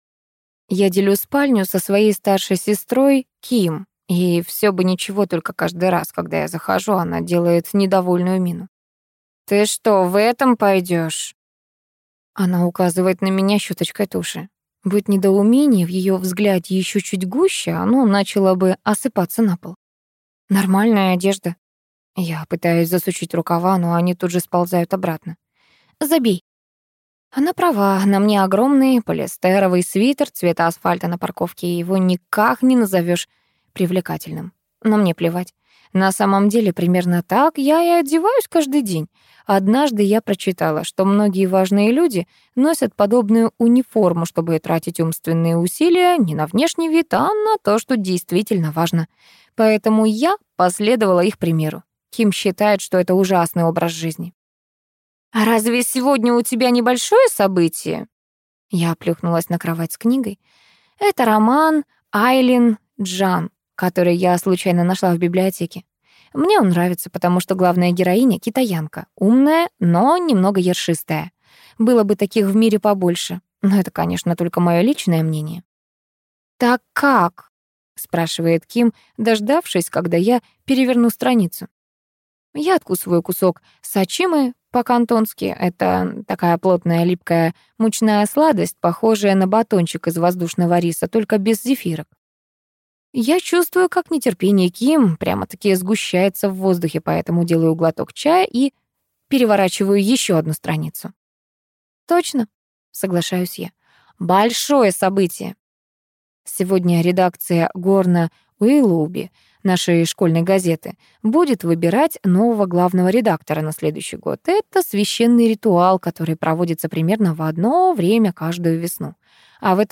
Аудиокнига Не доллар, чтобы всем нравиться | Библиотека аудиокниг